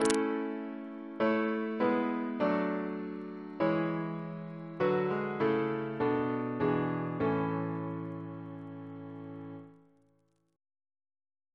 Single chant in A Composer: Sir John Goss (1800-1880), Composer to the Chapel Royal, Organist of St. Paul's Cathedral Reference psalters: ACB: 99; ACP: 117; H1940: 680; OCB: 137; PP/SNCB: 4; RSCM: 178